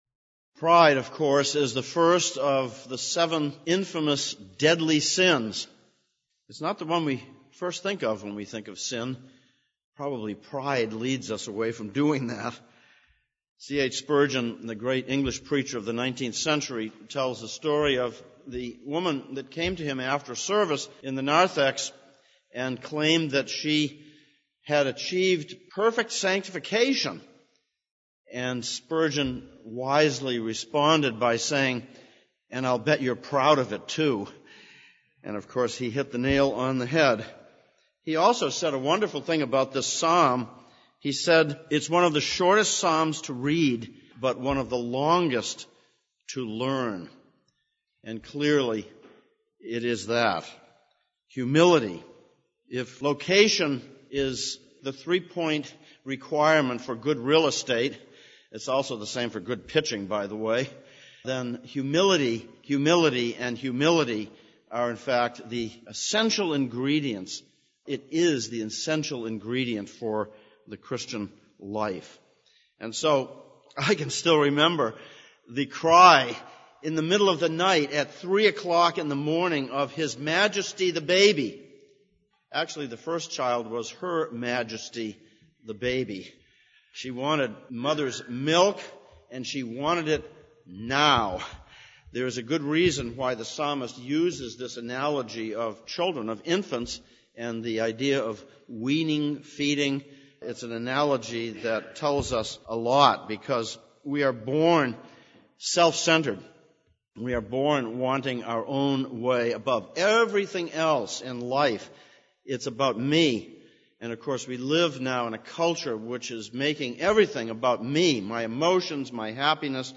Psalms of Ascents Passage: Psalm 113:1-3, Romans 12:14-21 Service Type: Sunday Morning « 14.